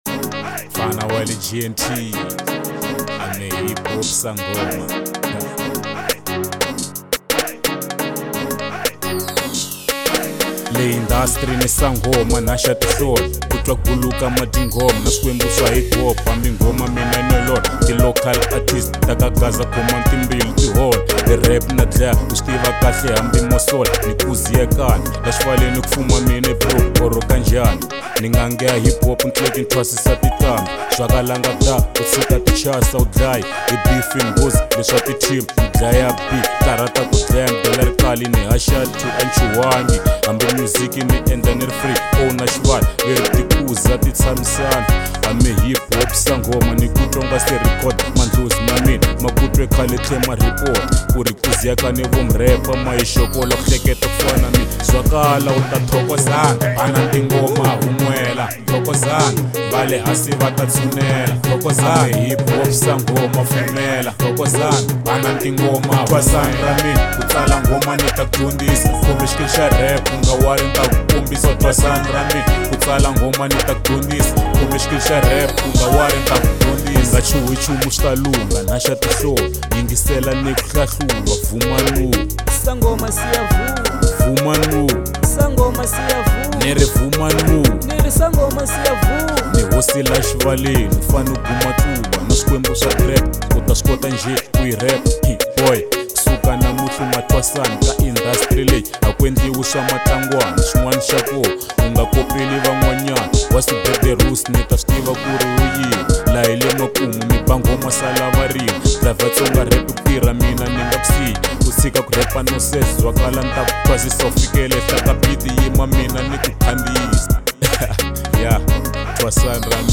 02:43 Genre : Hip Hop Size